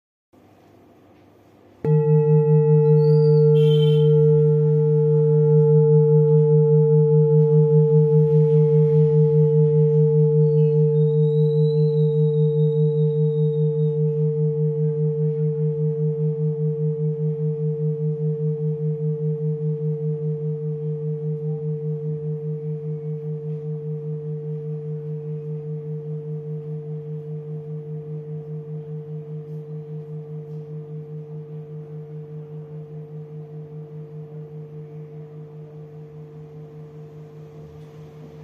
Buddhist Hand Beaten Full Moon Singing Bowl, Select Accessories
Material Bronze
This is a Himalayas handmade full moon singing bowl. The full moon bowl is used in meditation for healing and relaxation sound therapy.